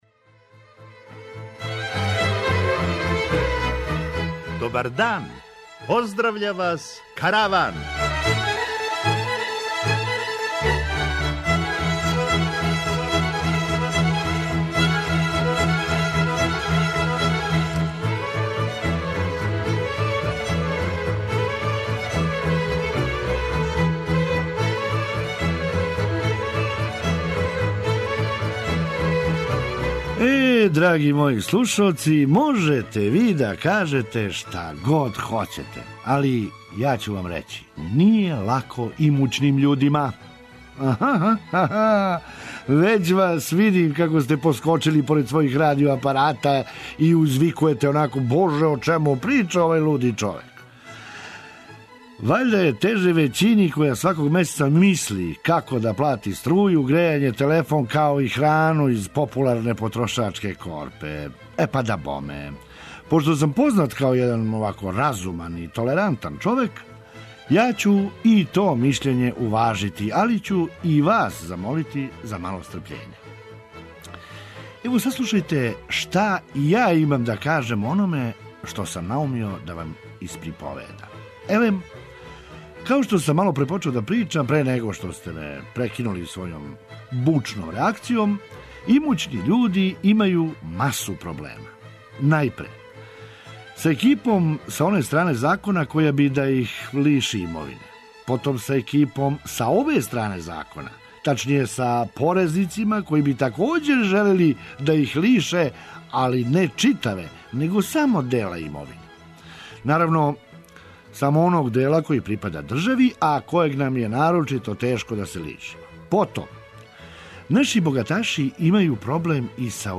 Наравно, није много весела, али је "зачињена" веселим инструменталом да је лакше "прогутате".